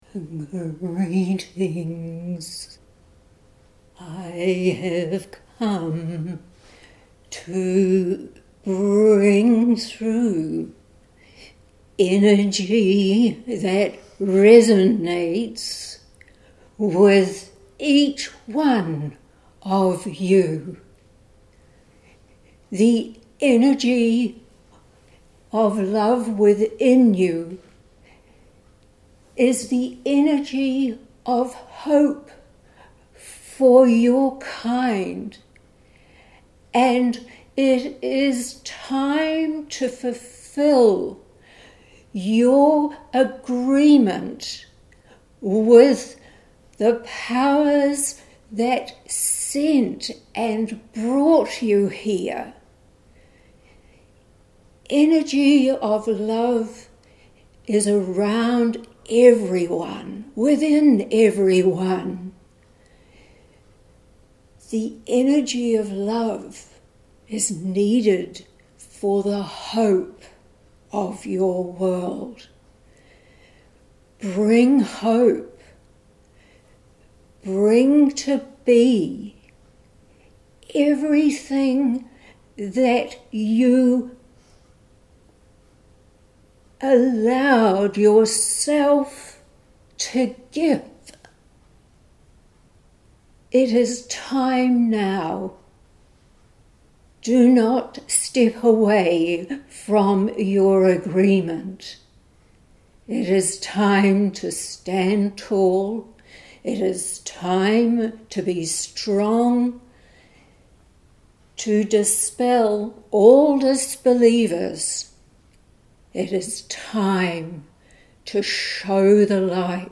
Posted in Audio recording, Metaphysical, Spirituality, Trance medium